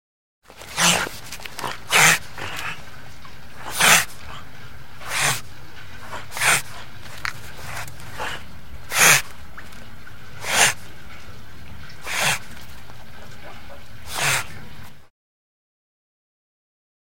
Хриплый вздох речной выдры